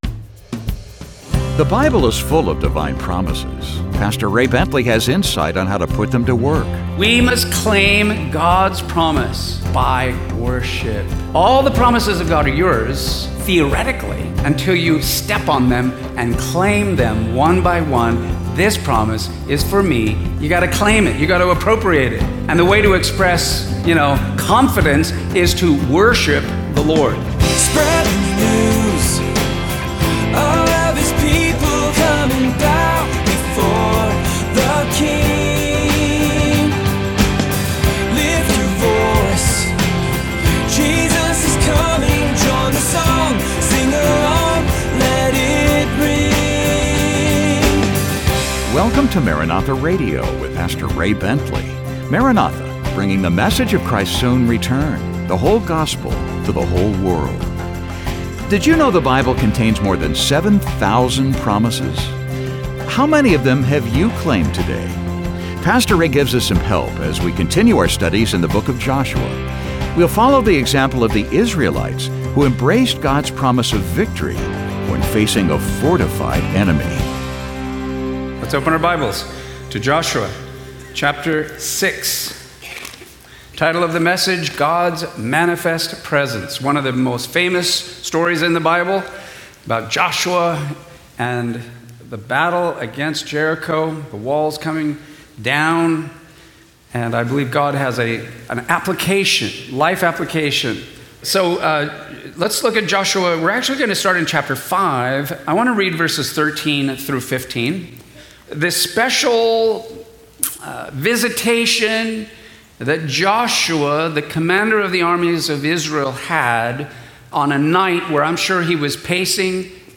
a daily teaching